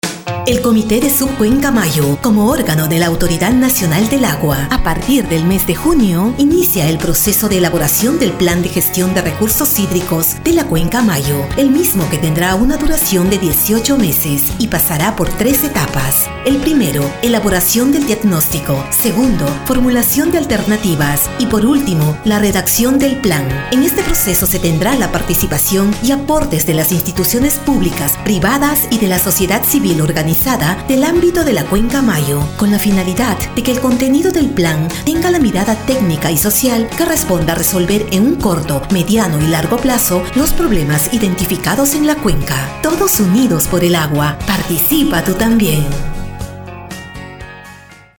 Spot: Plan de Gestión de Recursos Hídricos | Mayo